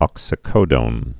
(ŏksĭ-kōdōn)